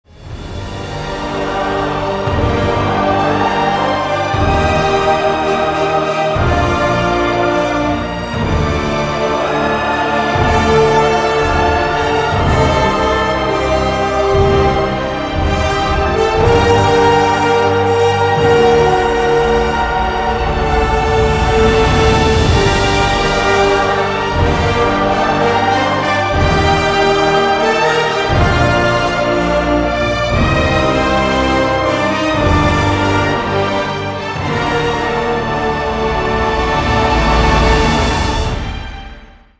• Качество: 320, Stereo
инструментальные
тревожные
хор
оркестр